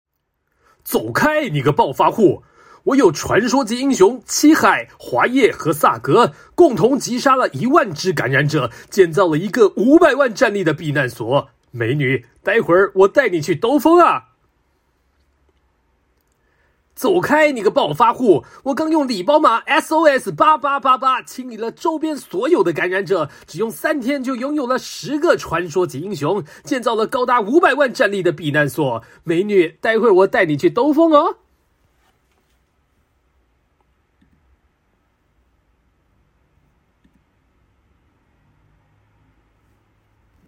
• 8台湾男声3号
游戏解说